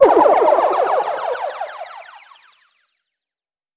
SouthSide Trap Transition (30).wav